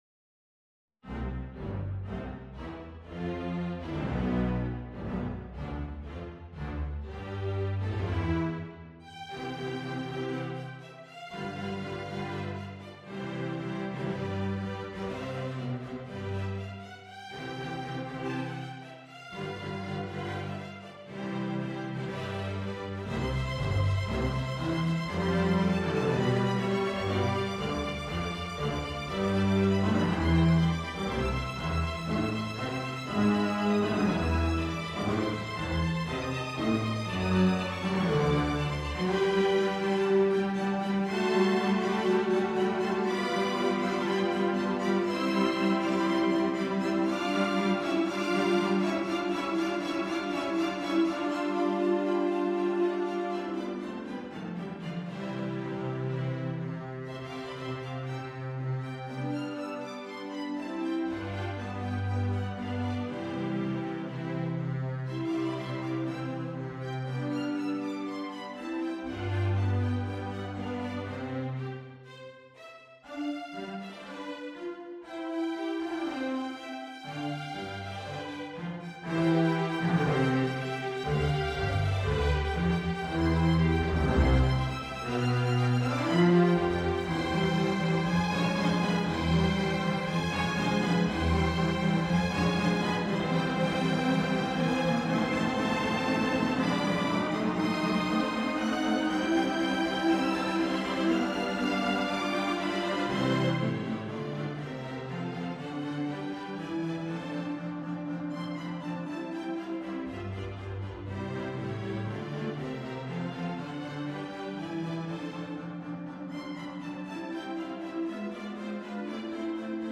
Piano Solo Flute Oboe Bassoon Horn 1 Trumpet 1 Timpani Violin 1 Violin 2 Viola Cello/Bass
Instrument: Piano and Ensemble
Style: Classical
mozart_piano_concerto_21_K467.mp3